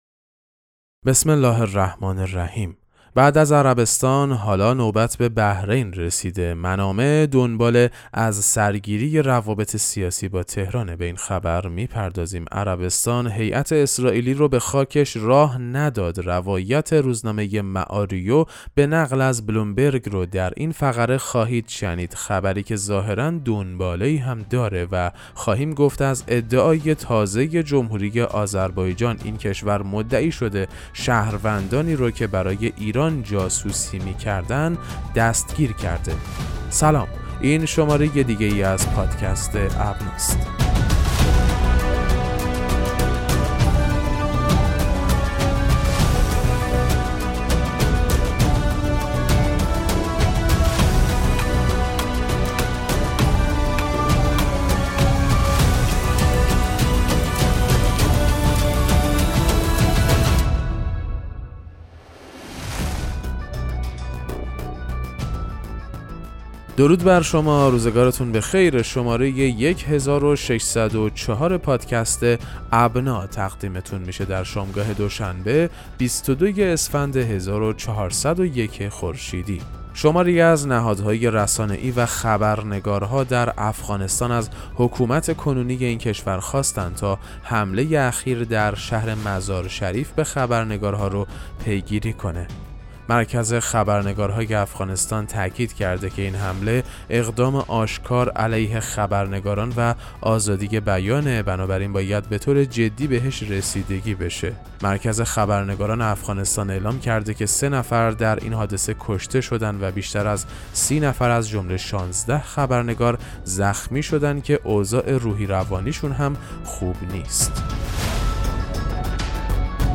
پادکست مهم‌ترین اخبار ابنا فارسی ــ 22 اسفند 1401